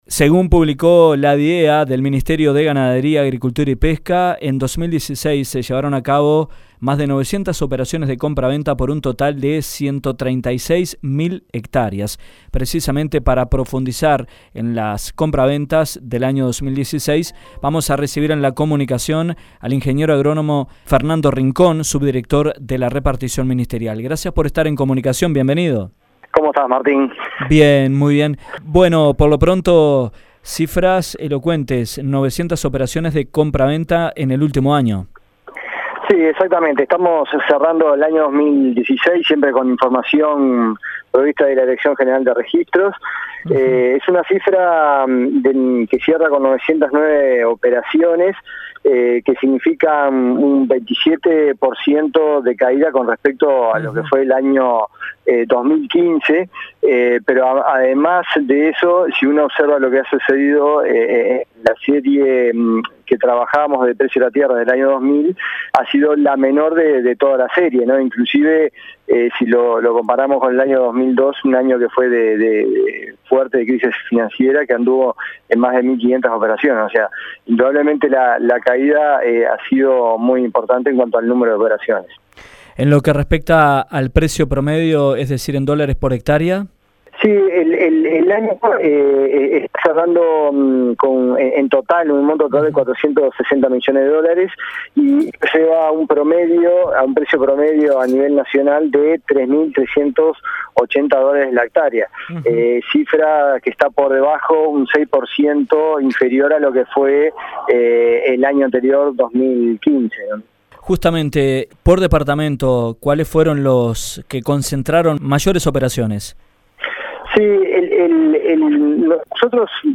Durante el 2016 se llevaron a cabo en Uruguay 909 operaciones de compraventa, un 27% menos que en el año anterior, por una superficie total de 136 mil hectáreas, según el informe "Precio de la Tierra" emitido por la Dirección de Estadísticas Agropecuarias del MGAP. En entrevista